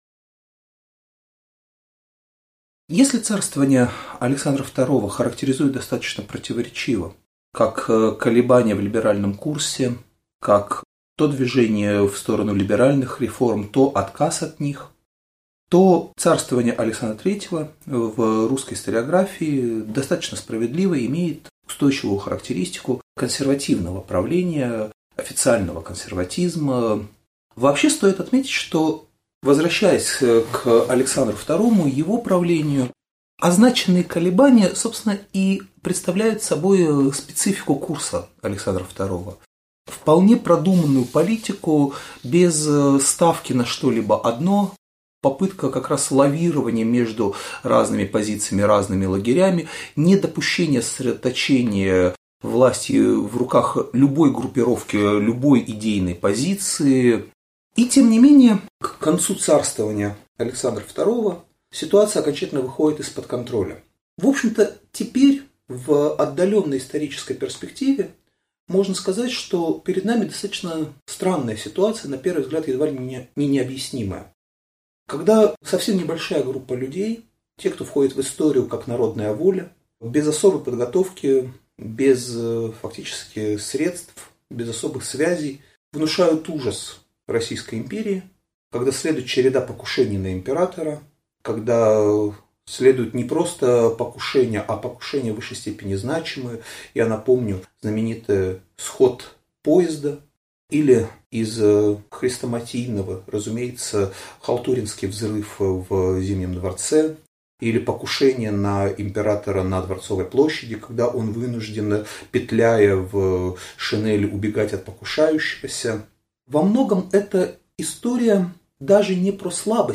Аудиокнига Лекция ««Контрреформы». 1880-е» | Библиотека аудиокниг